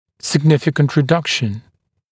[sɪg’nɪfɪkənt rɪ’dʌkʃn][сиг’нификэнт ри’дакшн]значительное уменьшение